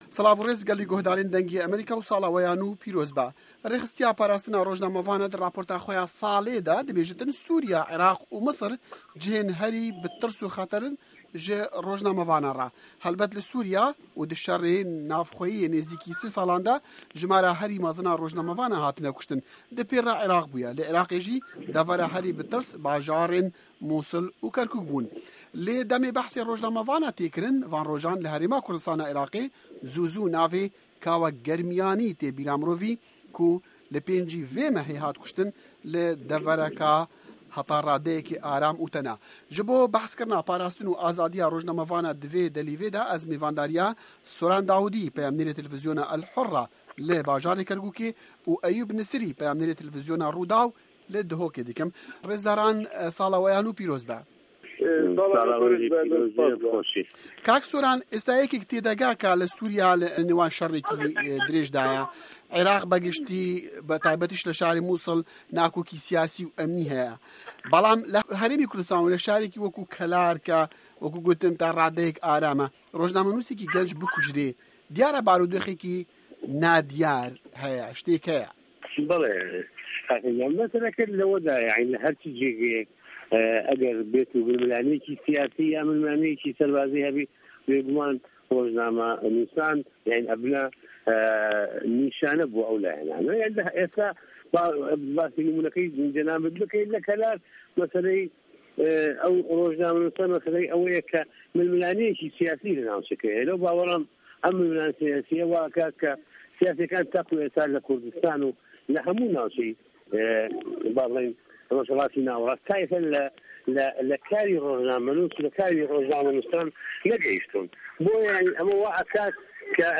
Hevpeyvîn Derbarê Rewşa Rojnamegeran li Sala 2013